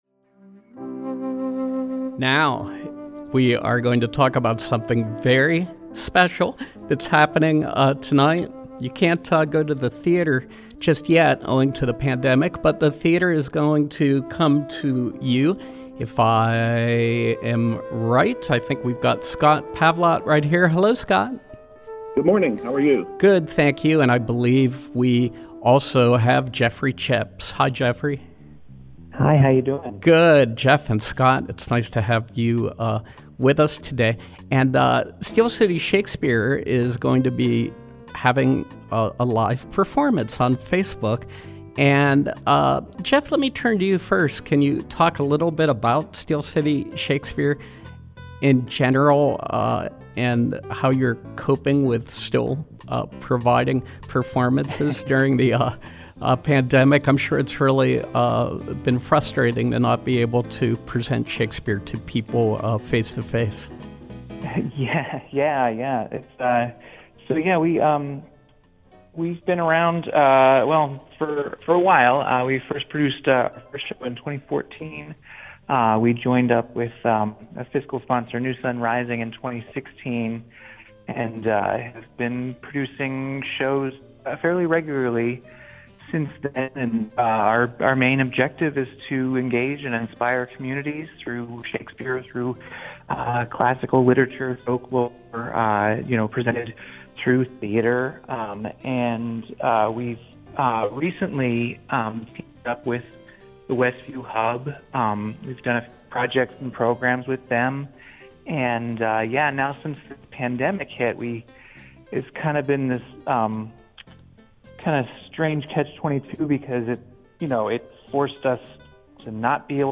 Interview: As You Like It, Steel City Shakespeare